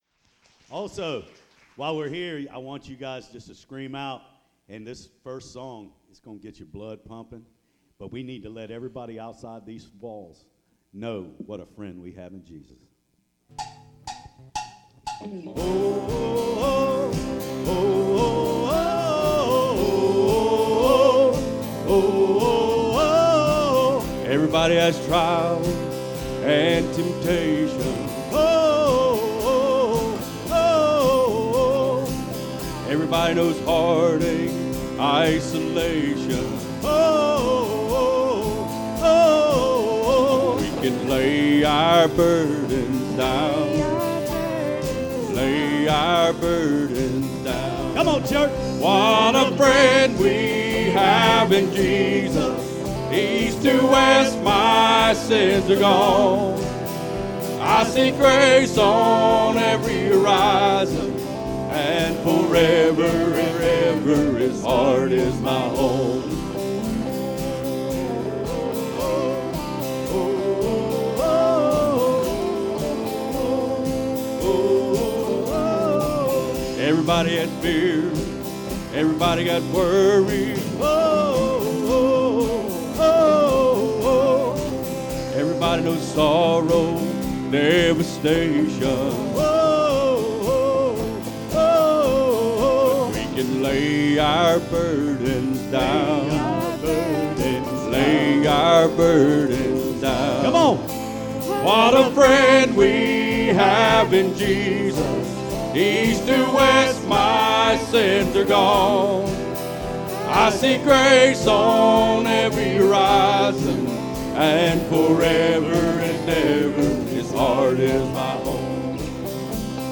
Sermons Archive - Page 2 of 14 - Life Point Church